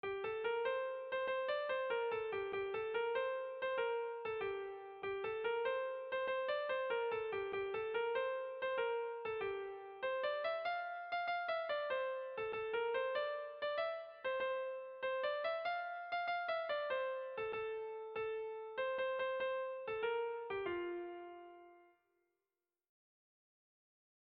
Tragikoa
Zortziko handia (hg) / Lau puntuko handia (ip)
AAB1B2